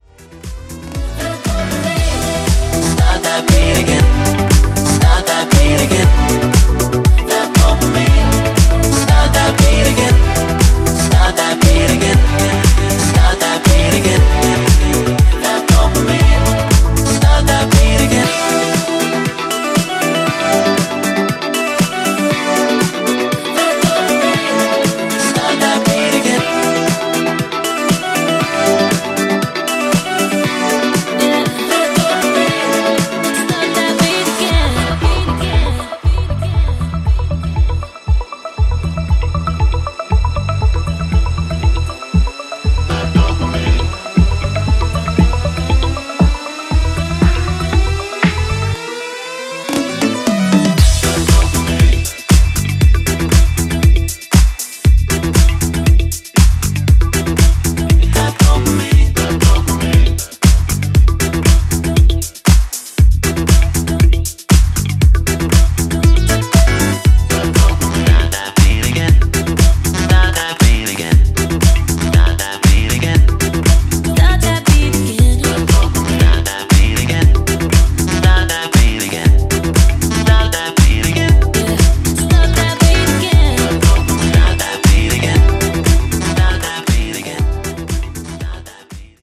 CLUB DUB
ジャンル(スタイル) HOUSE / NU DISCO / BALEARIC